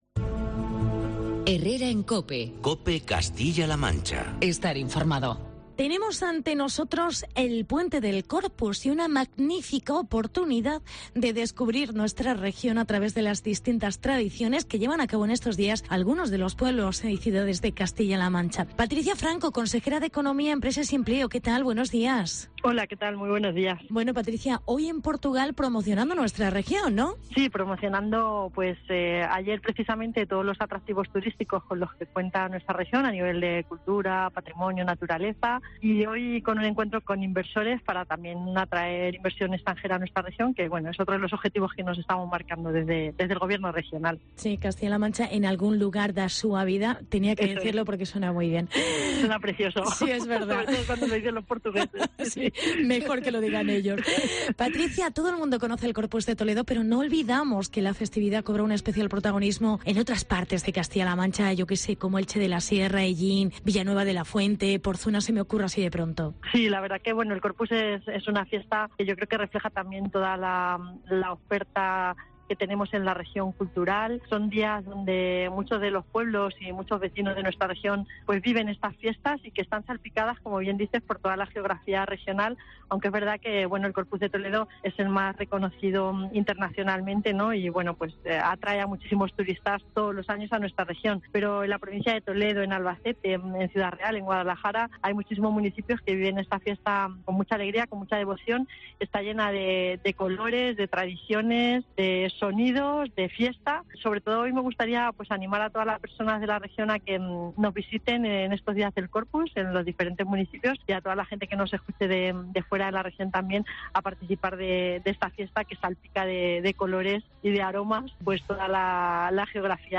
Entrevista Consejera de Economía: Patricia Franco